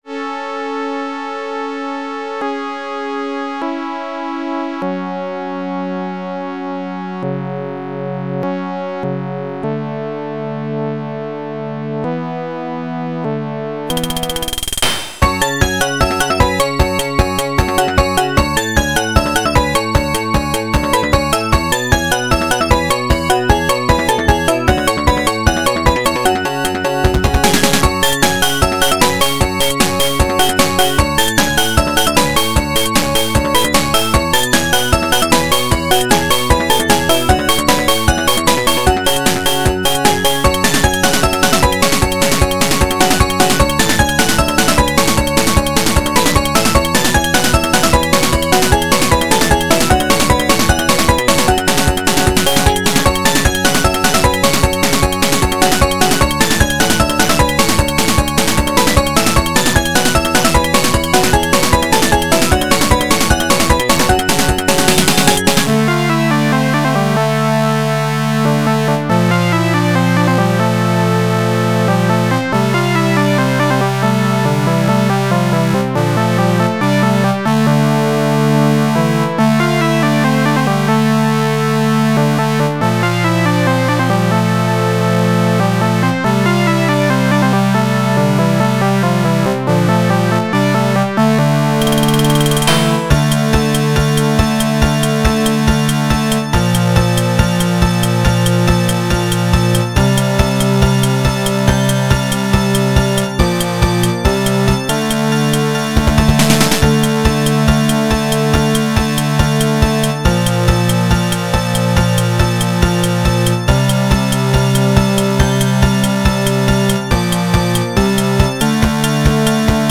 原游戏FM版，由PMDPlay导出。